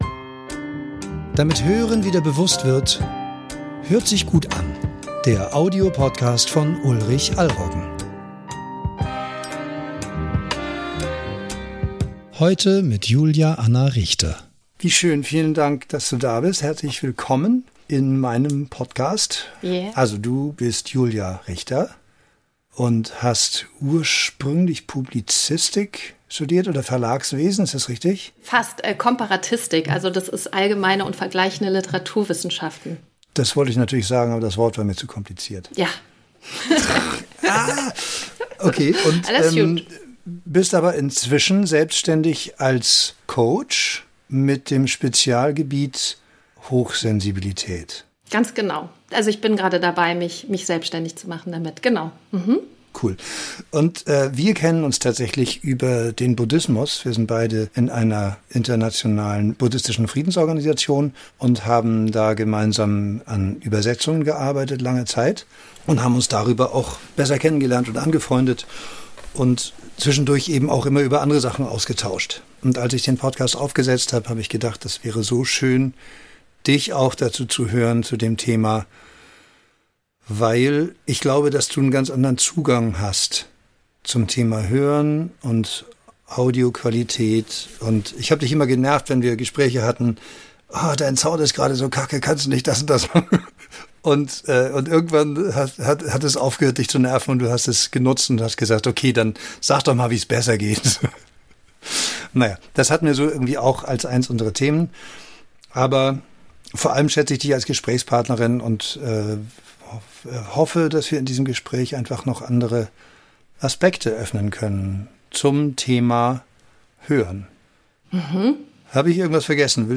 Zum Teil wird das Gespräch sehr persönlich, aber das soll so sein.